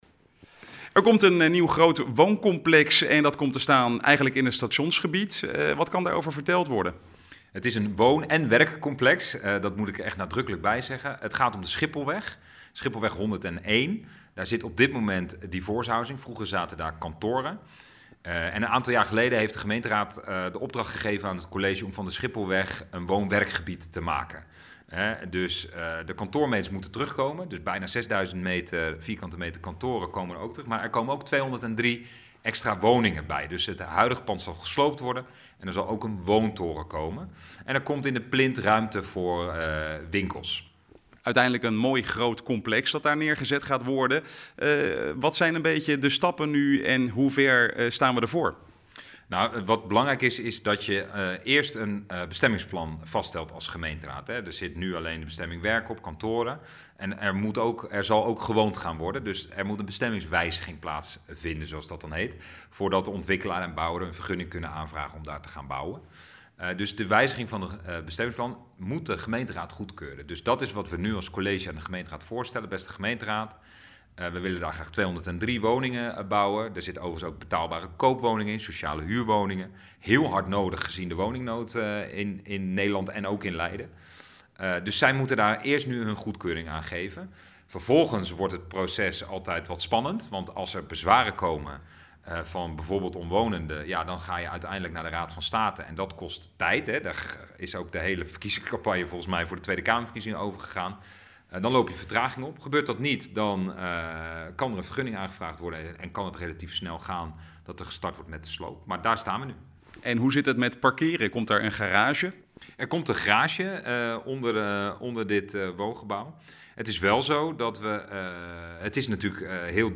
Wethouder Julius Terpstra over de Maretoren in Leiden: